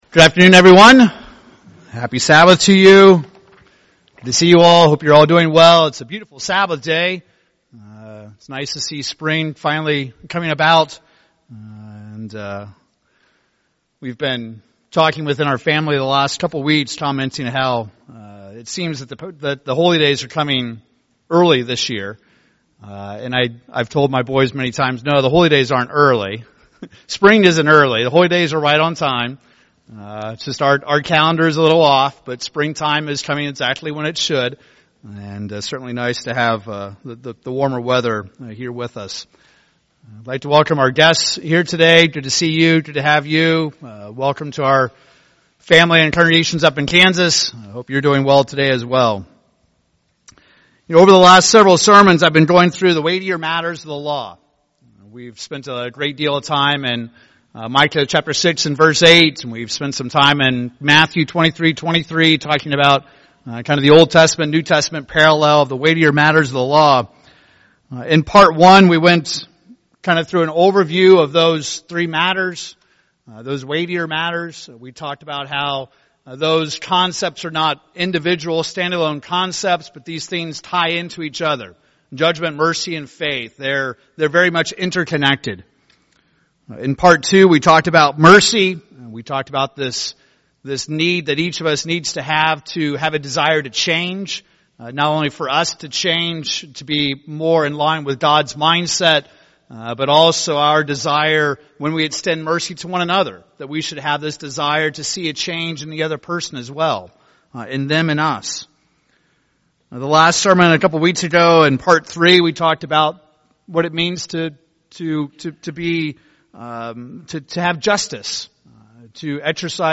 In this sermon we will conclude this sermon series on the Weightier Matters of the Law.